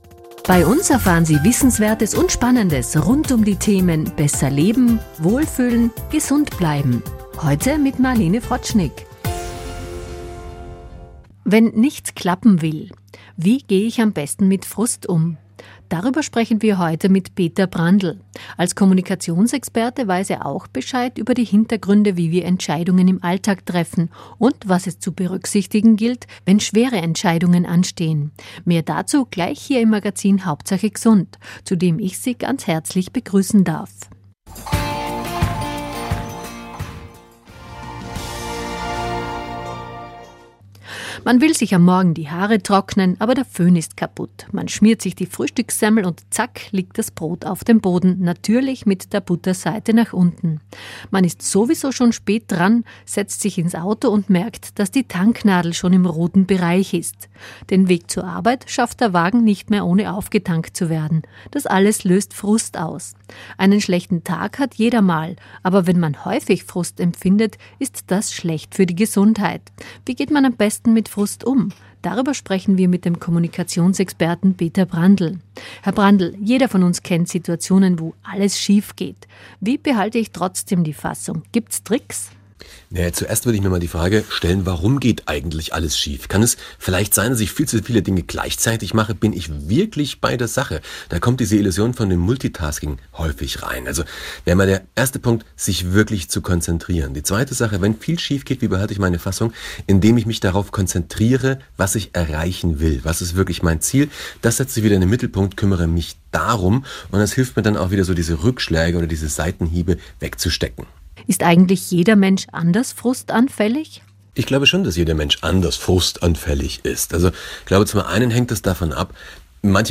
Interview in Radio Osttirol